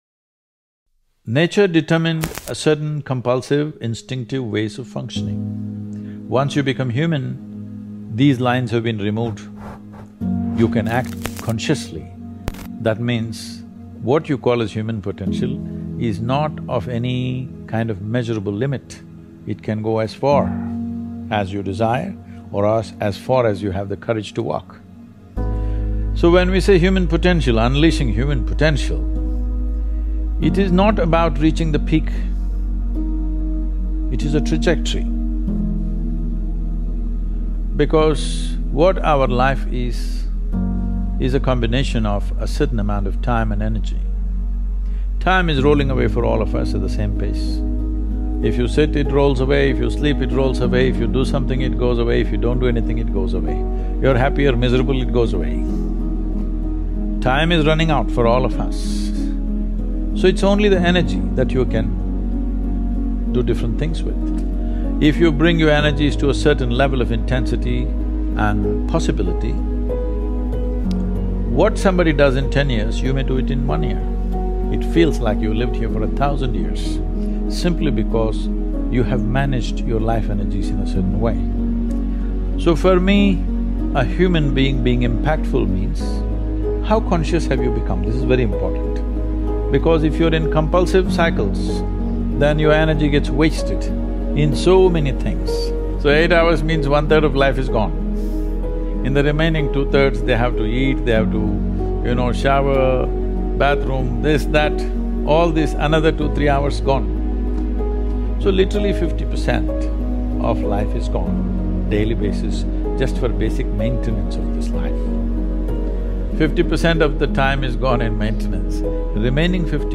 Speech on Human Consciousness: Ideas That Deepen Self-Awareness and Calm